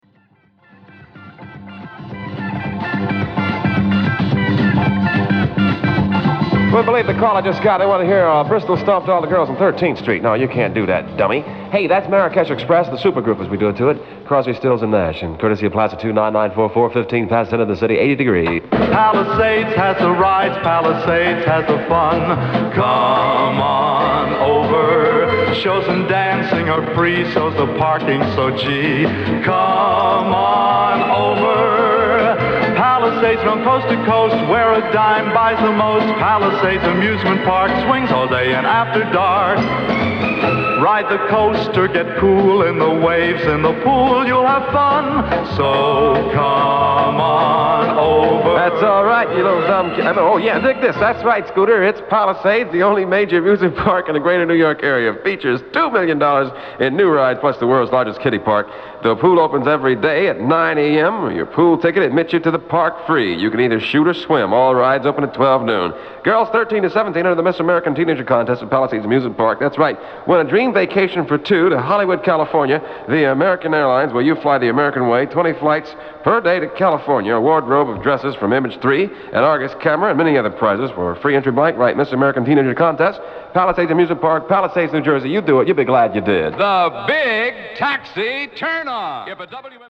WMCA commercial